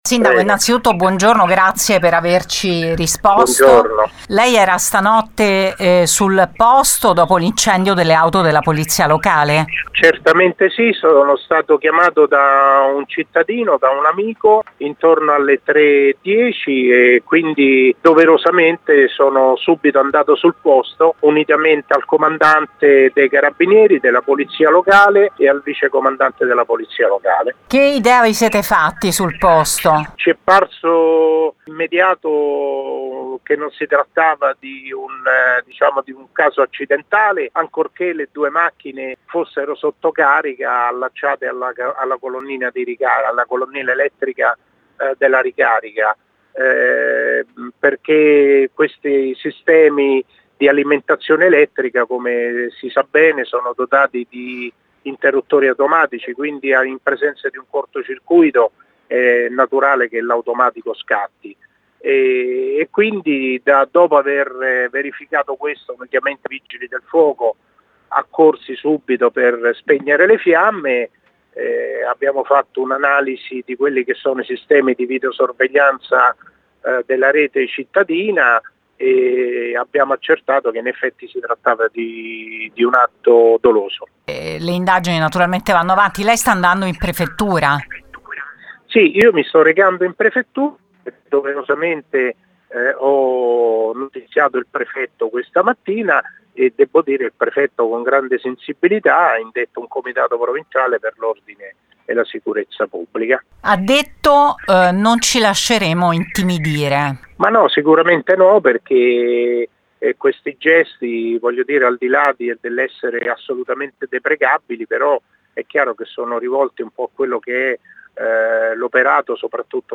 Ne abbiamo parlato con lui per Gr Latina